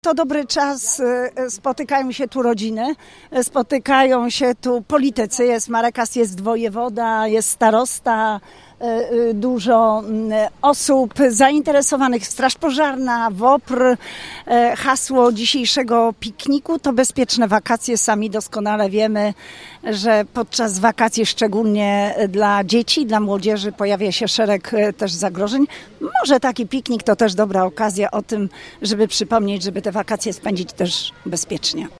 Wśród zaproszonych gości na Pikniku Rodzinnym PiS w Szlichtyngowej pojawiła się Europoseł Elżbieta Rafalska:
piknik-szlichtyngowa-elzbieta-rafalska-3.mp3